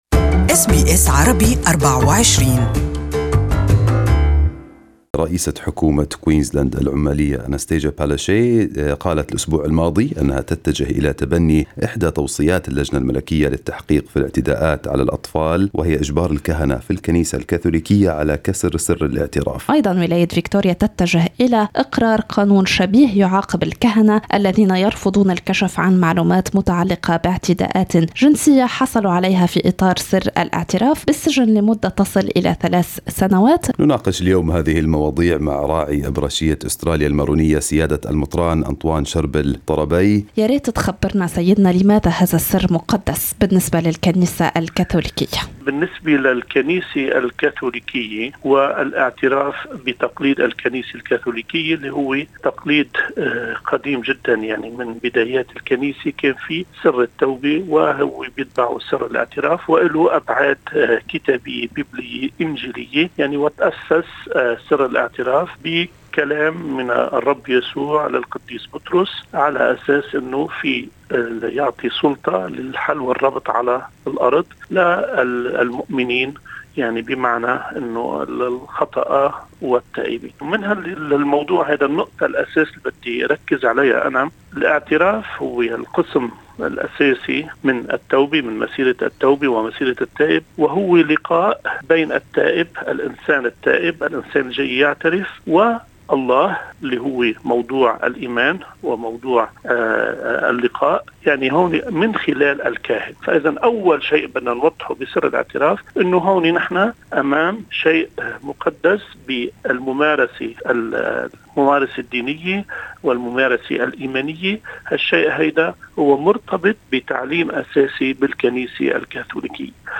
This interview is available in Arabic.